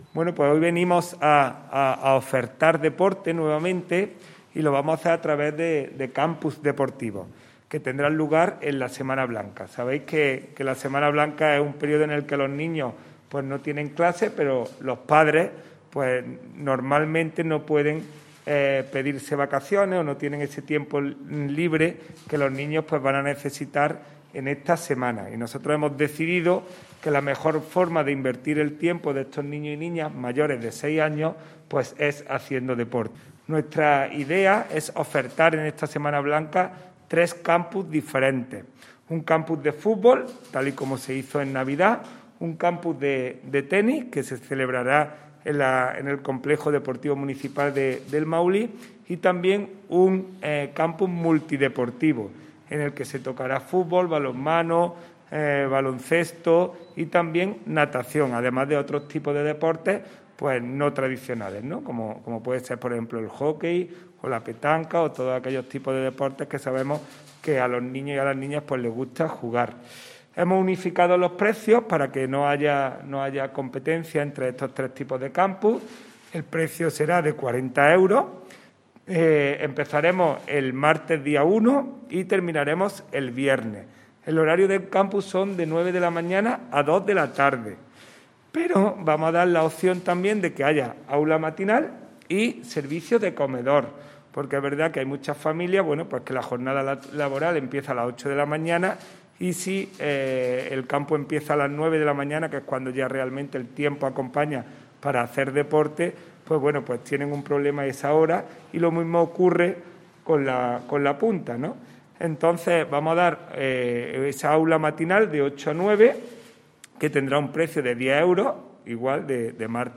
El teniente de alcalde delegado de Deportes, Juan Rosas, ha presentado hoy en rueda de prensa una nueva iniciativa del Área de Deportes a desarrollar con motivo de los días escolares no lectivos que conlleva el tradicional desarrollo en nuestra provincia de las denominadas como vacaciones de Semana Blanca.
Cortes de voz